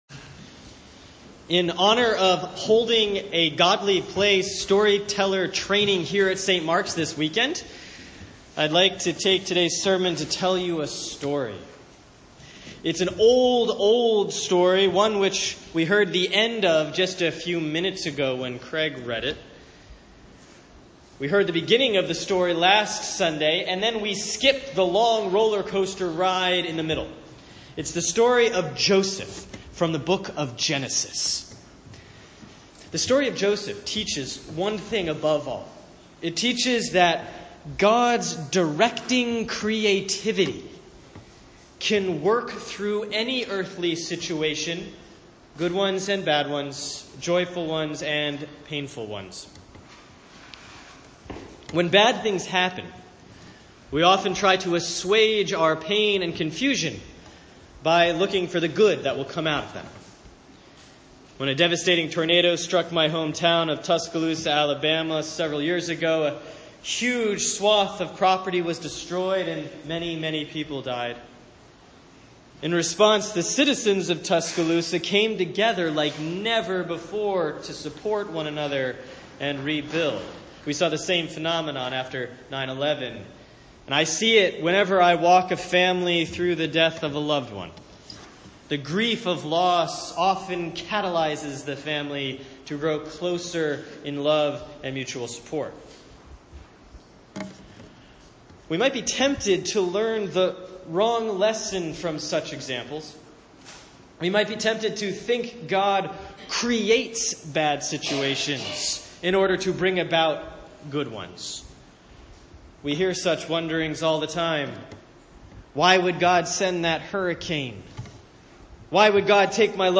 Sunday, August 20, 2017 || Proper 15A || Genesis 37-47